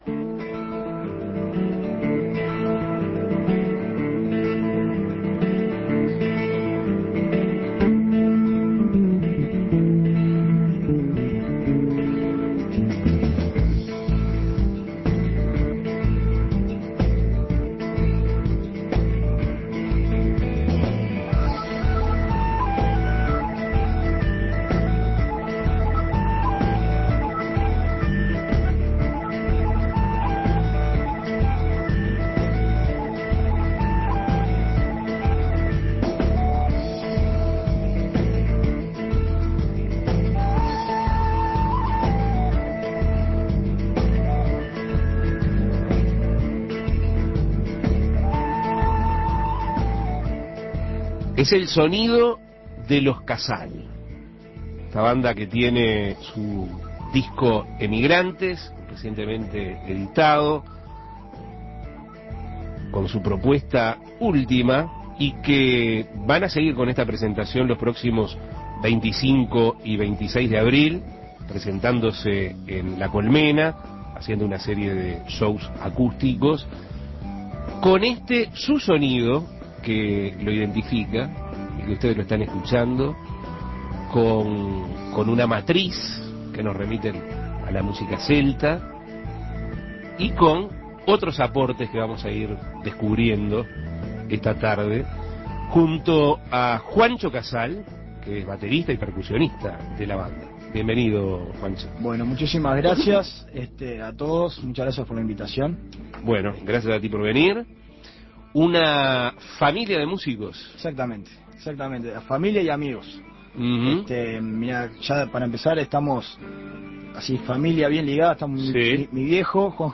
Entrevistas Los Casal presenta su nuevo disco Emigrantes Imprimir A- A A+ Los Casal es un grupo que fusiona instrumentos de tímbrica celta con música contemporánea.